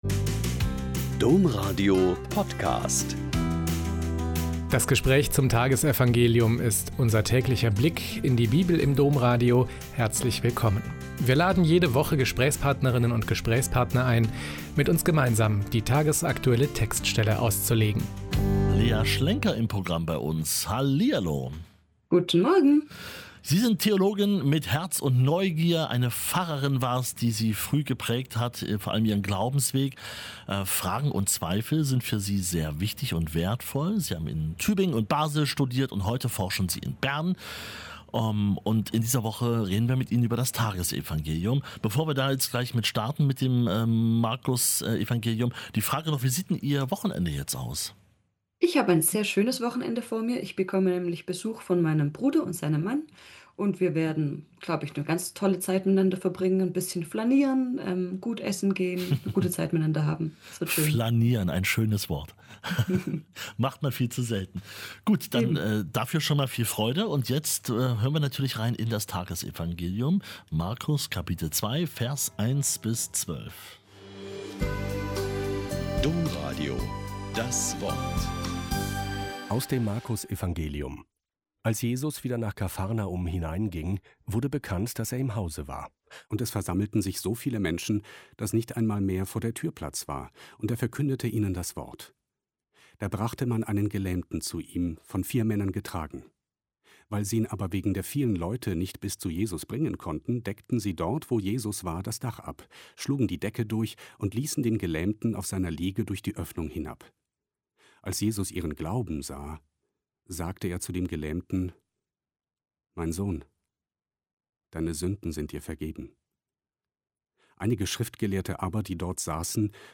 Mk 2,1-12 - Gespräch